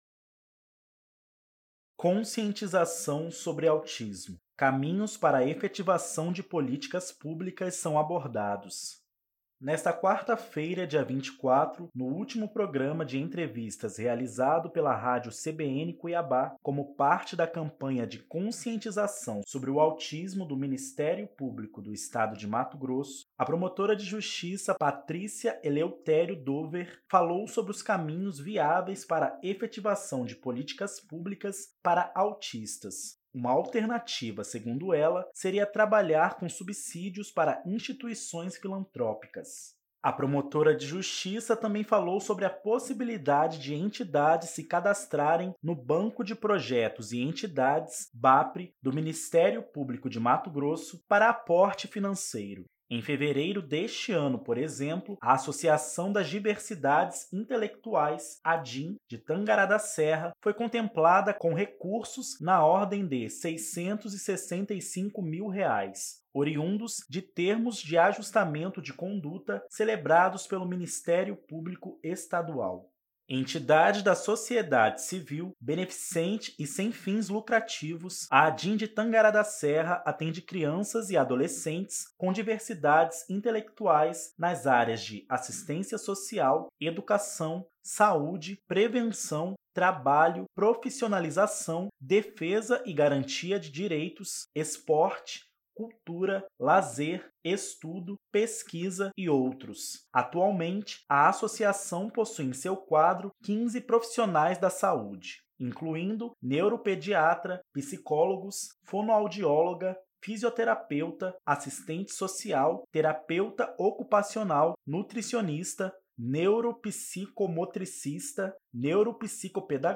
Nesta quarta-feira (24), no último programa de entrevistas realizado pela Rádio CBN Cuiabá, como parte da campanha de conscientização sobre o autismo do Ministério Público do Estado de Mato Grosso, a promotora de Justiça Patrícia Eleutério Dower falou sobre os caminhos viáveis para efetivação de políticas públicas para autistas. Uma alternativa, segundo ela, seria trabalhar com subsídios para instituições filantrópicas.